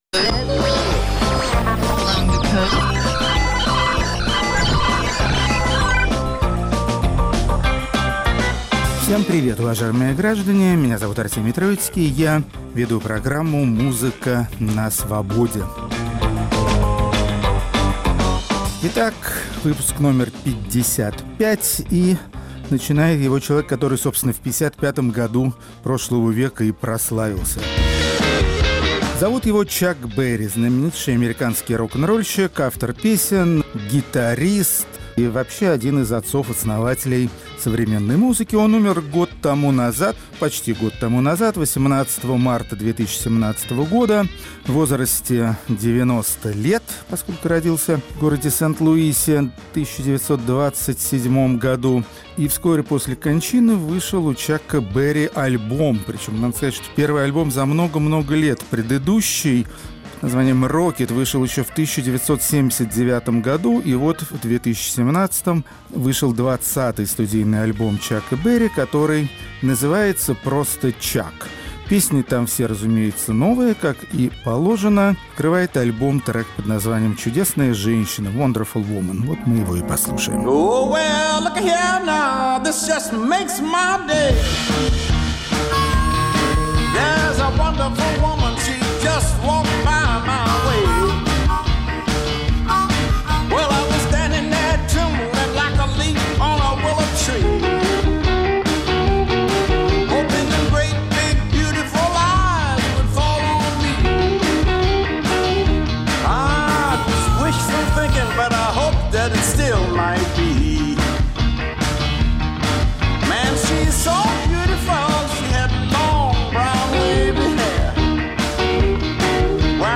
Исполнители психоделических рок-композиций. Рок-критик Артемий Троицкий считает это направление современного музыкального творчества популярным, известным, скандальным и... не совсем понятным